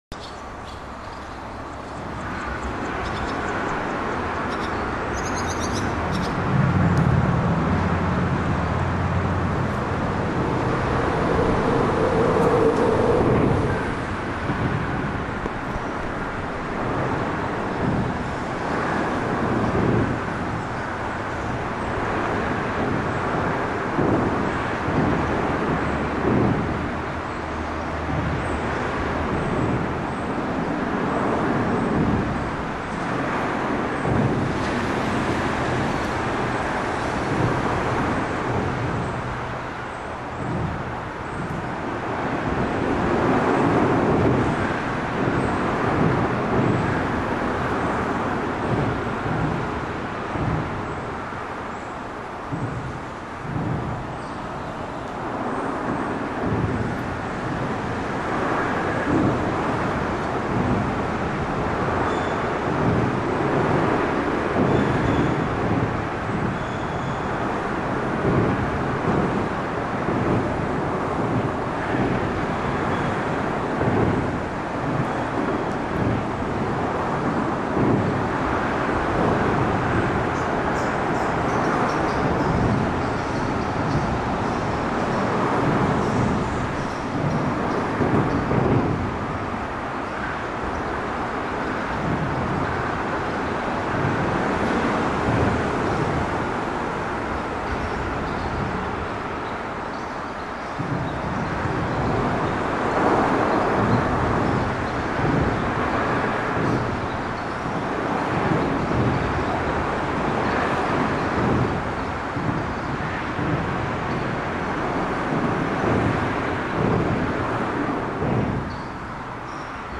Colorless injection molded earbuds drown cardinal song, eddies whispering rivulet secrets, and eighteen wheelers thumping down the concrete and steel bypass twenty feet above.
overpass.mp3